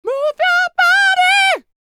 DD FALSET101.wav